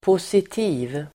Uttal: [p'os:iti:v]